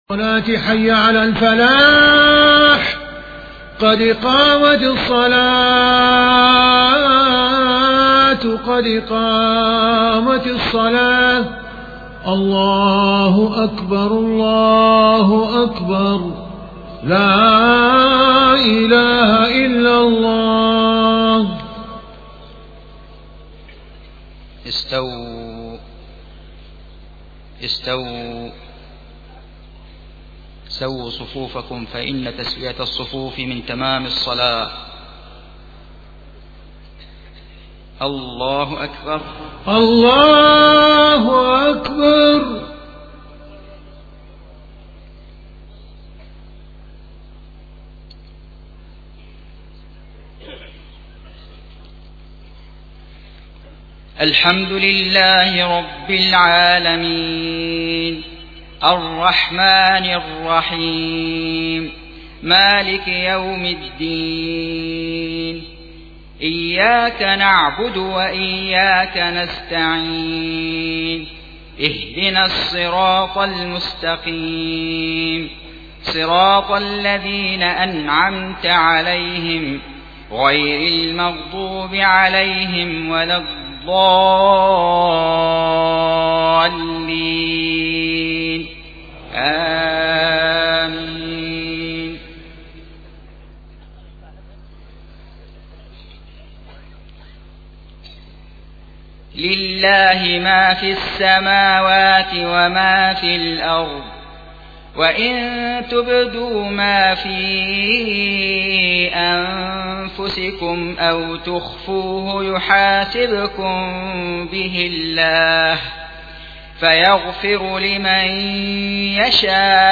صلاة المغرب 5 محرم 1429هـ خواتيم سورة البقرة 284-286 > 1429 🕋 > الفروض - تلاوات الحرمين